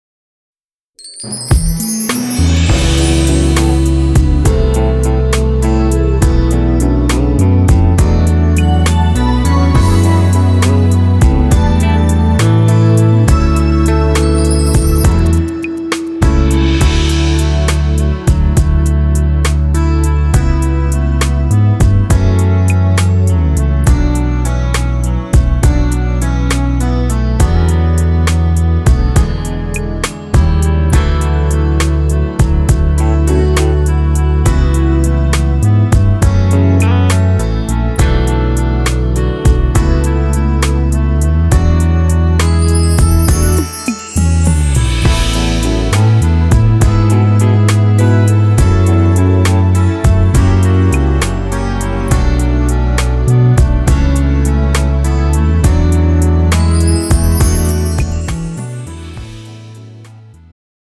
음정 남자키
장르 축가 구분 Pro MR